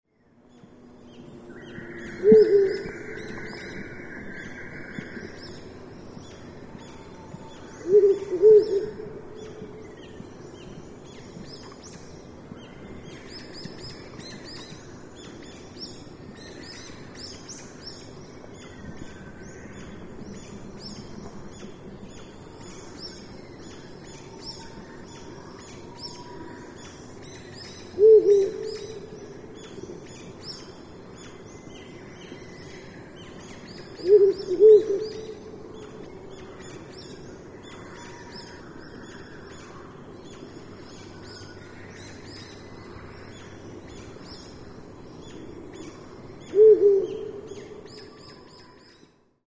hukuro_s1.mp3